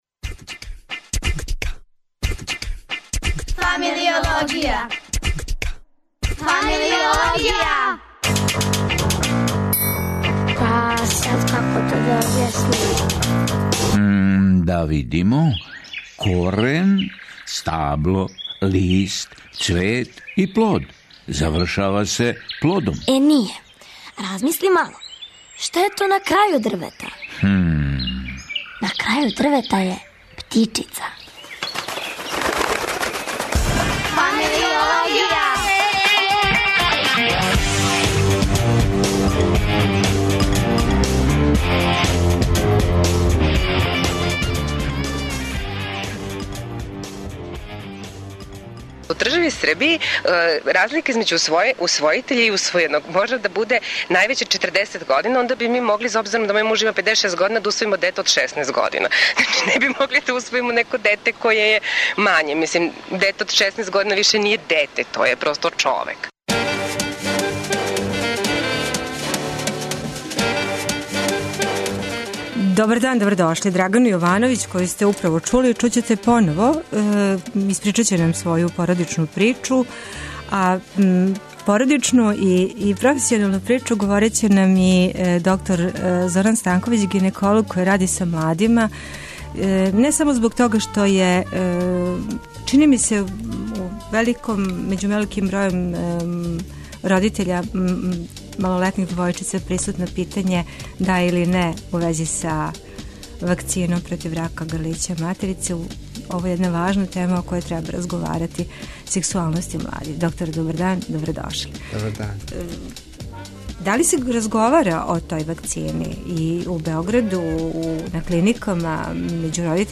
Реагујемо и на малу матуру, говори једна мајка...